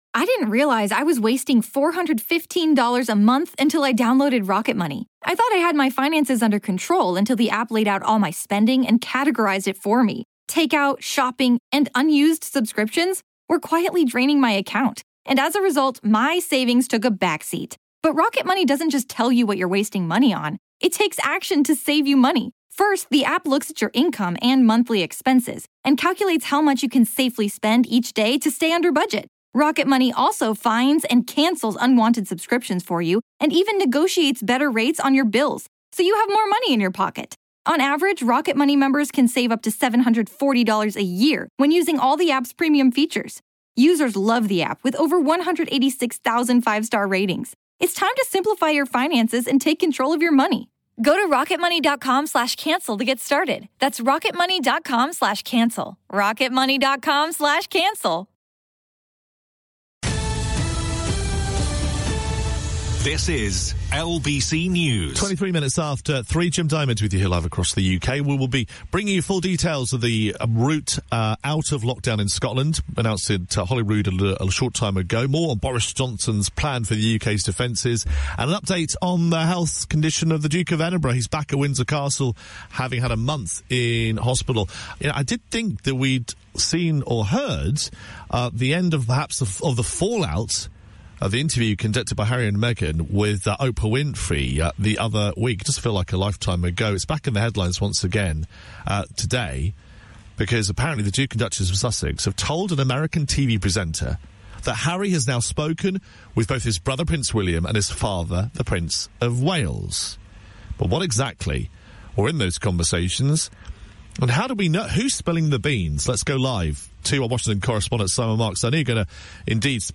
live report for LBC News